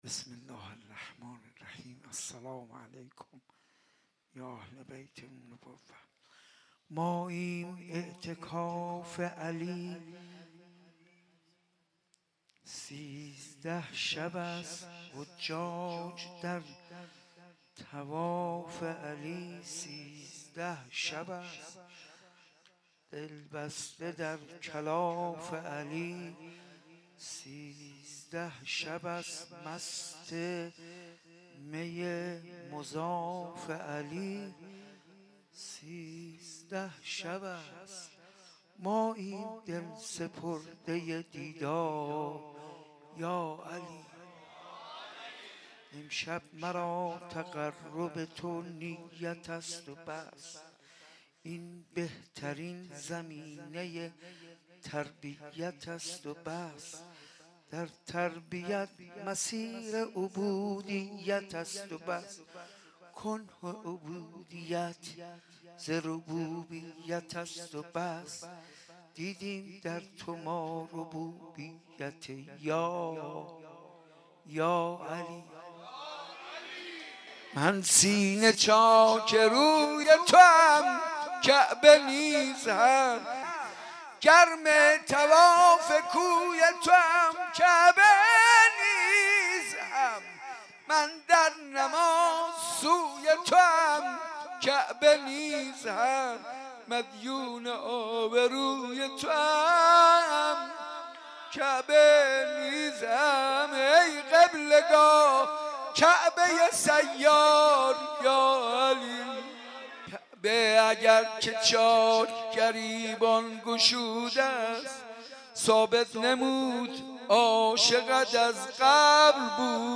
ین مراسم با نماز جماعت مغرب و عشاء و تلاوت قرآن کریم آغاز و با سخنرانی حجت الاسلام و المسلمین
مولودی خوانی
نکاتی به اختصار بیان نمودند و به مدیحه سرایی پرداختند.